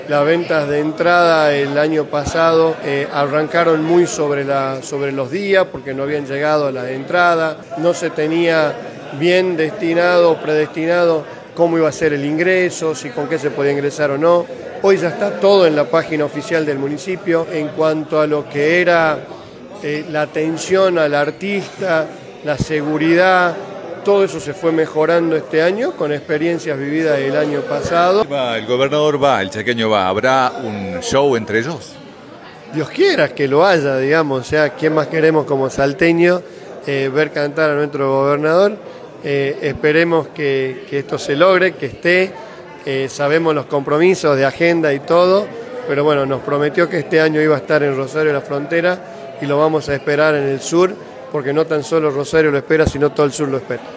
En dialogo con Actualidad Salta, el Intendente de Rosario de la Frontera Kuldeep Singh se esperanzo con un dúo entre el Gobernador Sáenz y el Chaqueño Palavecino.
En conferencia de prensa el intendente del lugar, kuldeep Sing, aseguro la participación del Gobernador Gustavo Sáenz en la fiesta Chayera.